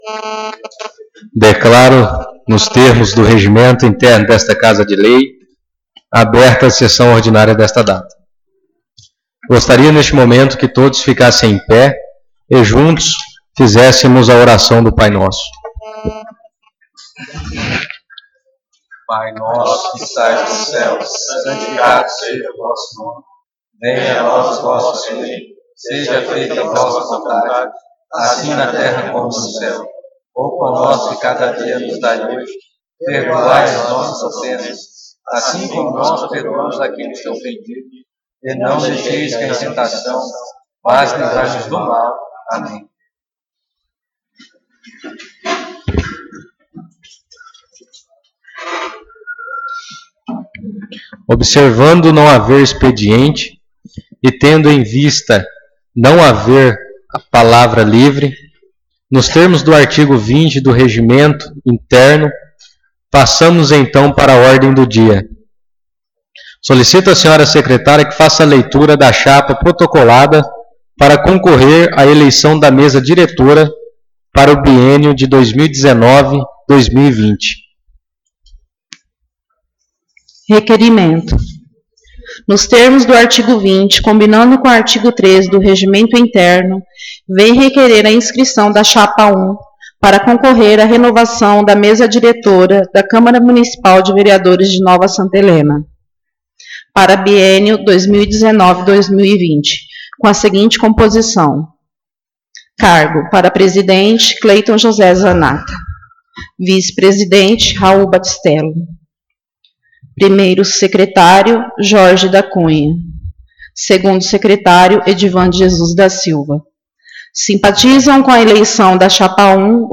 Sessão Ordinária 05/11/2018